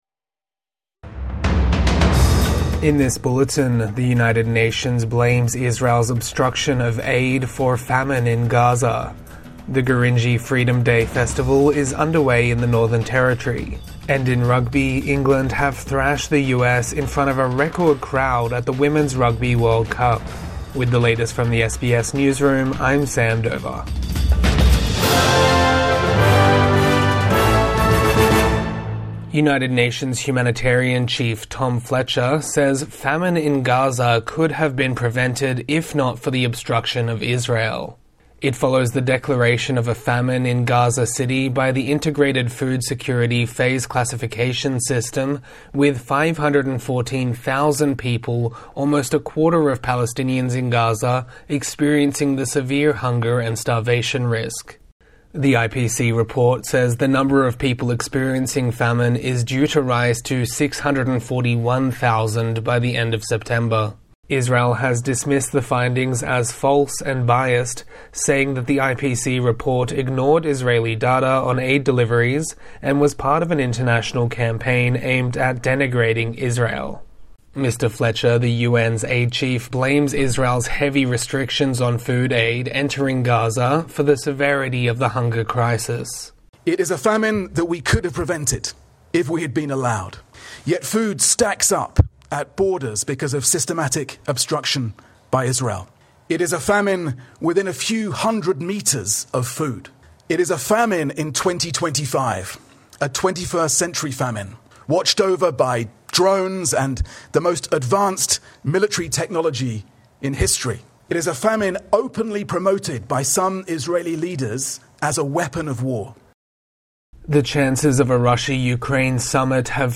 UN aid chief blames Israeli policies for Gaza famine | Midday News Bulletin 23 August 2025